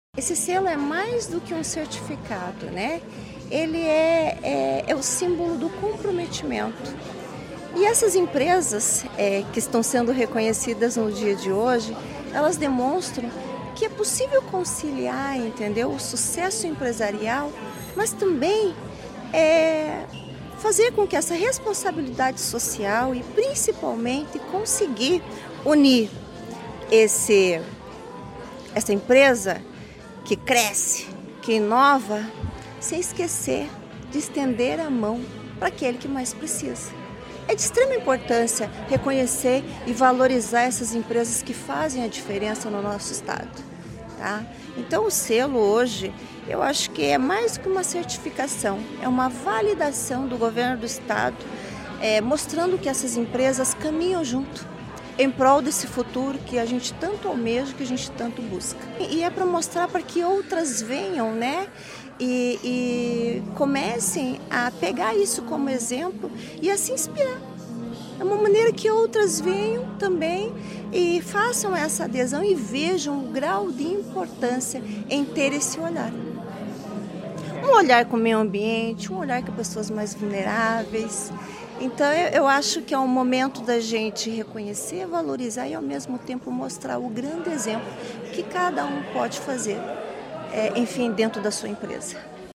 Sonora da primeira-dama do Paraná, Luciana Saito Massa, sobre o Selo Solidário para empresas que promovem desenvolvimento social e sustentável